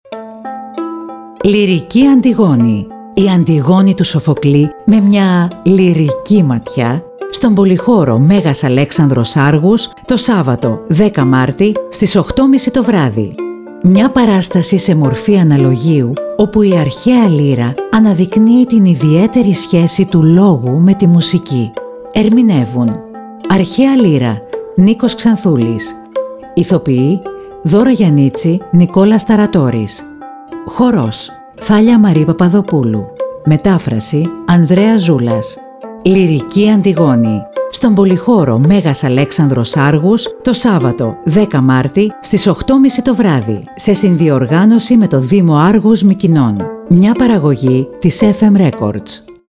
Греческий Культурный Центра (ГКЦ) рад пригласить Вас на новую постановку ЛИРИЧЕСКАЯ АНТИГОНА по Софоклу в сопровождении аутентичной древнегреческой музыки, в исполнении воссозданной античной лиры, хора и видеоинсталляции